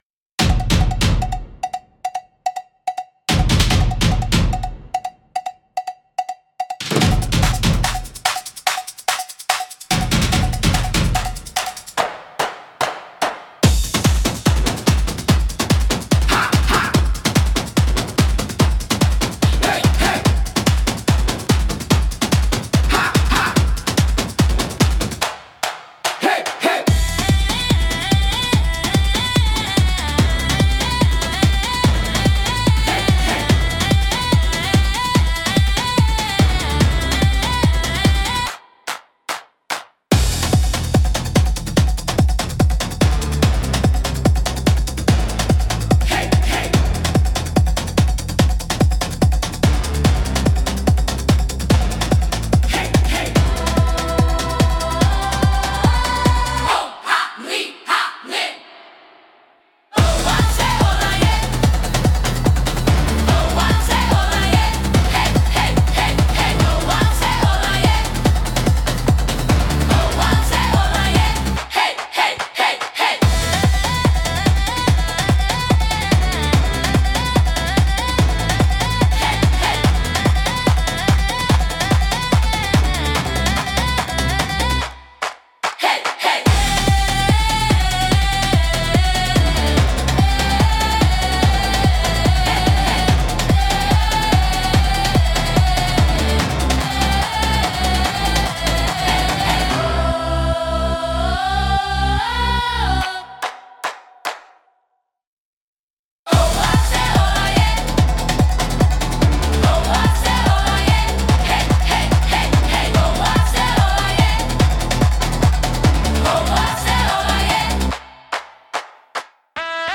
オリジナルのトライバルは、民族的な打楽器やリズムが中心となり、神秘的で原始的な雰囲気を持つジャンルです。
繰り返しのリズムと独特のメロディが、古代的かつエネルギッシュな空気感を作り出します。
聴く人に深い興味や未知への探求心を喚起し、エキゾチックな異世界感を演出します。迫力と神秘性が共存するジャンルです。